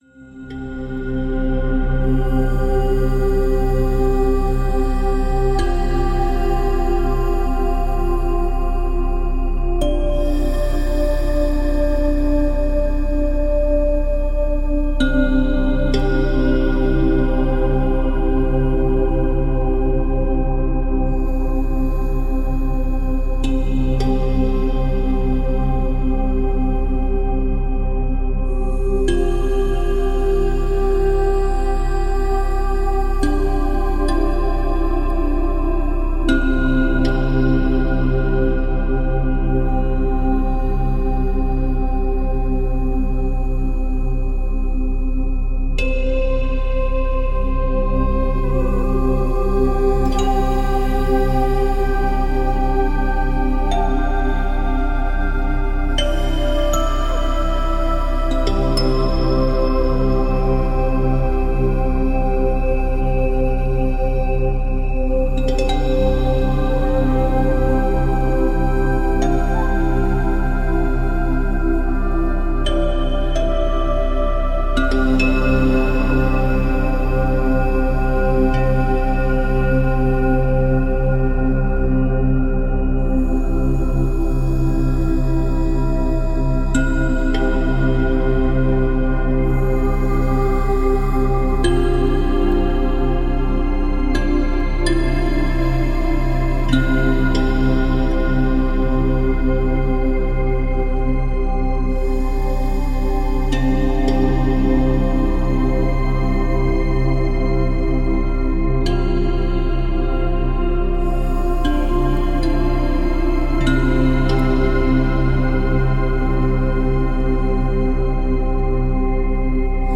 FRÉQUENCES VIBRATOIRES